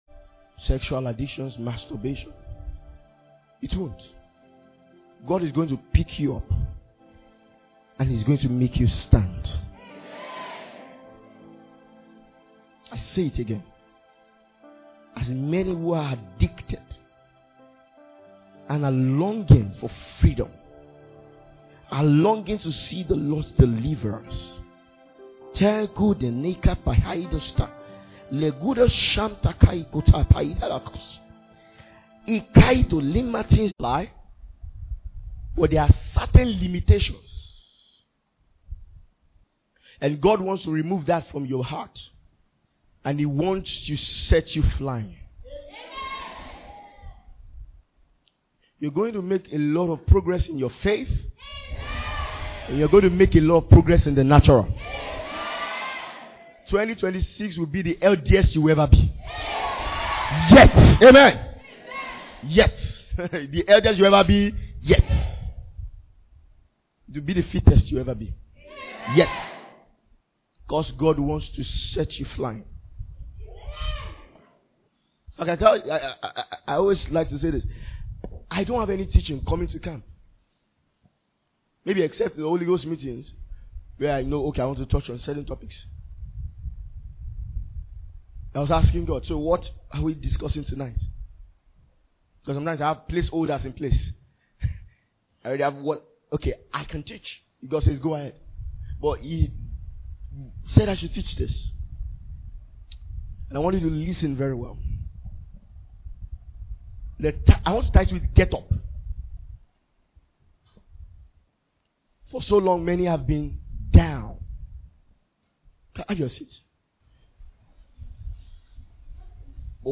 Message from our annaul Ministers’ Retreat 2025